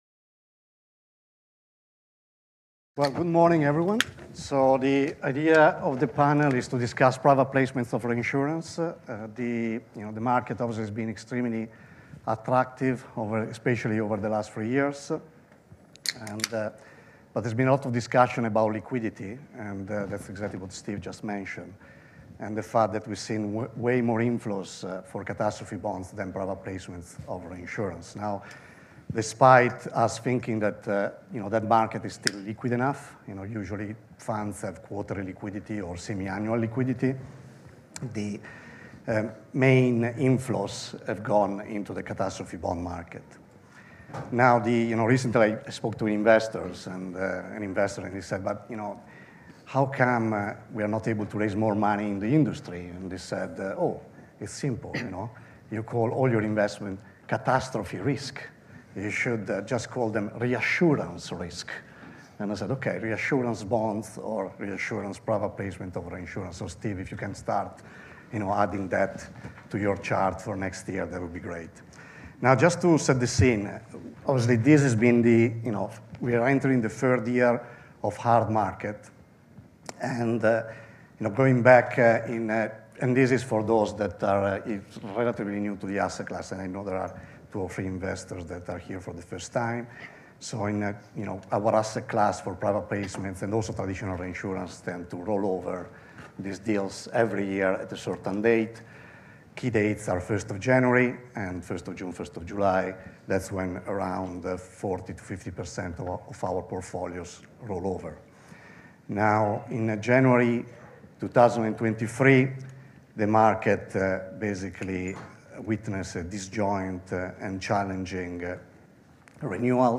This episode features the first panel session of the day at our Artemis ILS NYC 2025 conference, which was held on February 7th in New York City, which was focused on the collateralized reinsurance and retrocession side of the market, titled: Private ILS strategies, primed for expansion and growth?